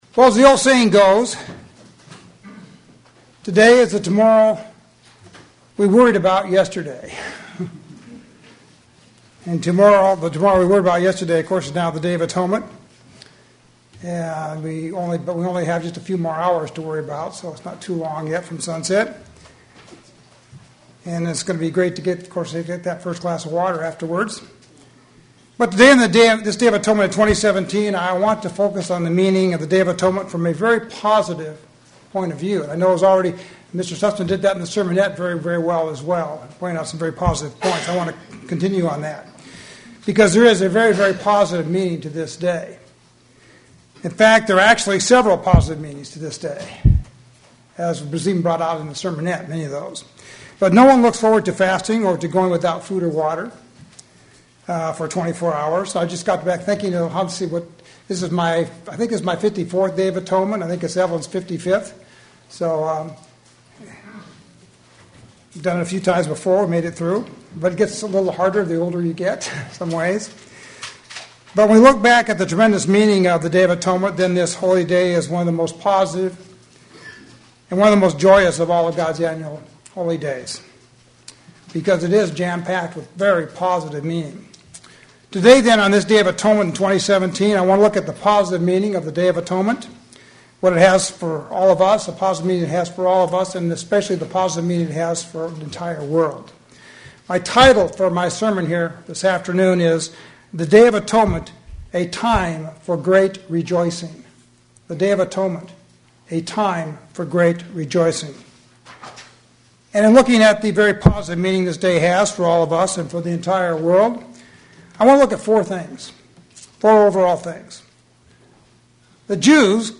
Actually, it is a perfect opportunity for rejoicing. sermon Transcript This transcript was generated by AI and may contain errors.